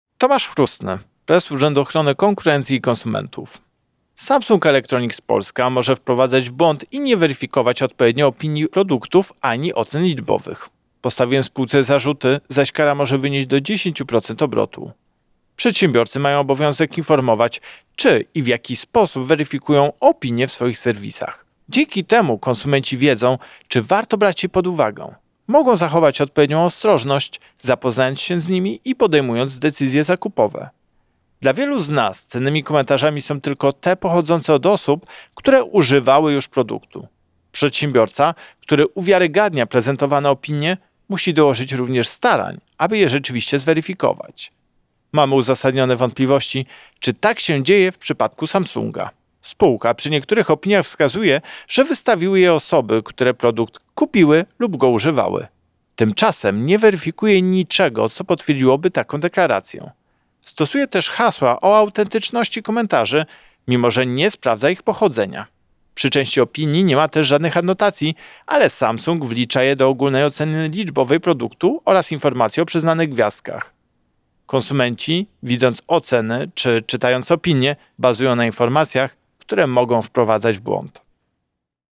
Wypowiedź Prezesa UOKiK Tomasza Chróstnego